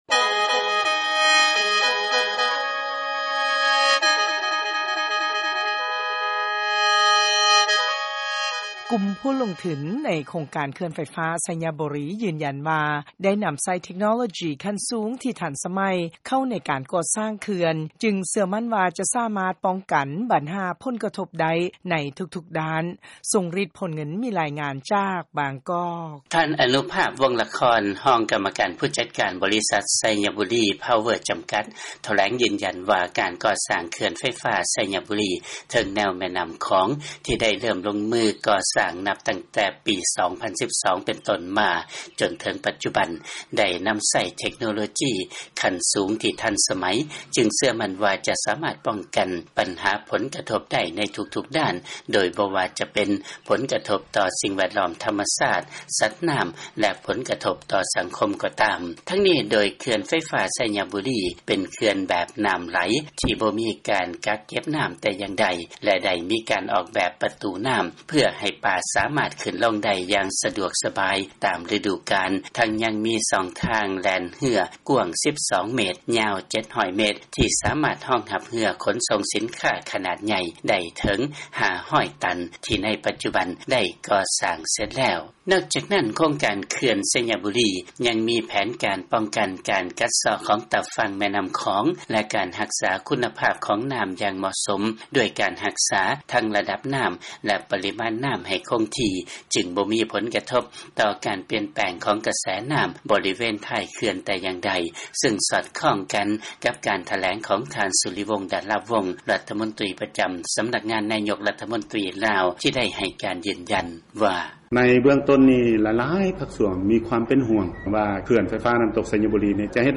ເຊີນຟັງ ລາຍງານ ເຂື່ອນໄຟຟ້າໄຊຍະບູລີ ກໍ່ສ້າງຂຶ້ນ ດ້ວຍເທັກໂນໂລຈີ ຂັ້ນສູງ ທີ່ທັນສະໄໝ.